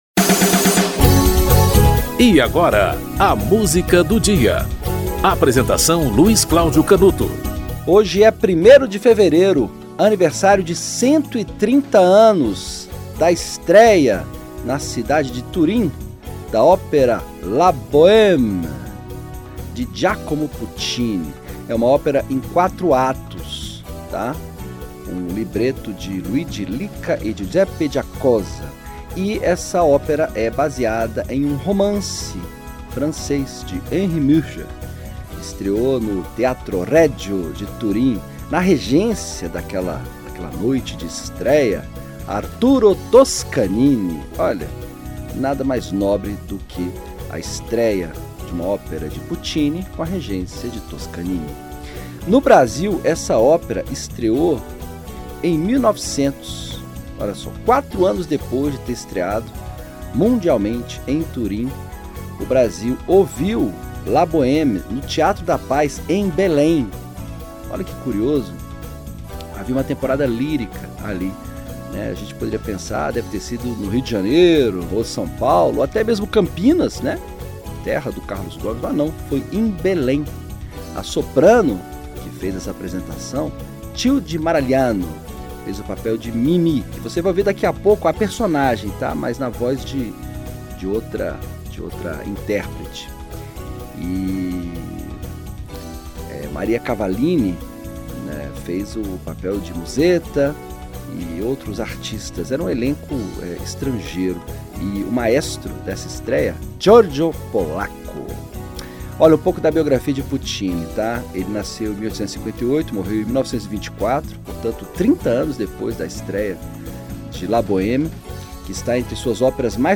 Anna Netrebko e Yuri Temirkanov e Orquestra Filarmônica de São Petersburgo - Quando m'en vo (Giacomo Puccini)